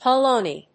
/pɑˈloni(米国英語), pɑ:ˈləʊni:(英国英語)/